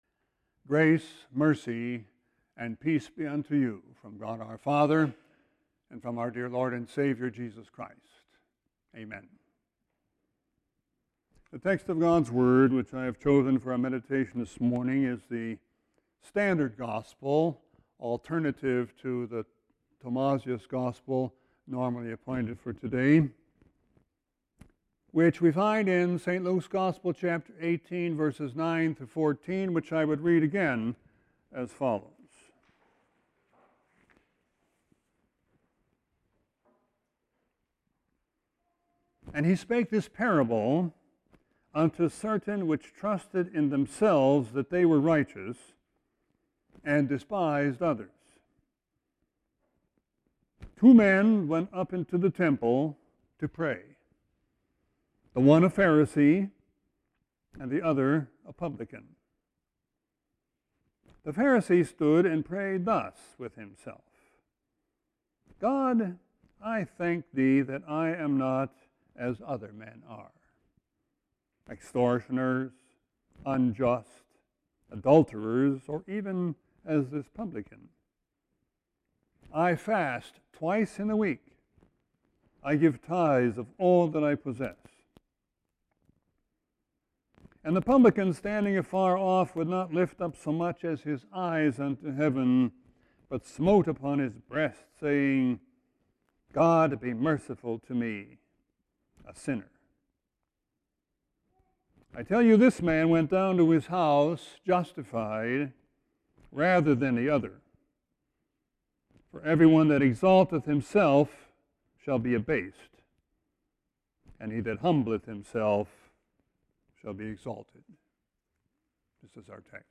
Sermon 8-27-17.mp3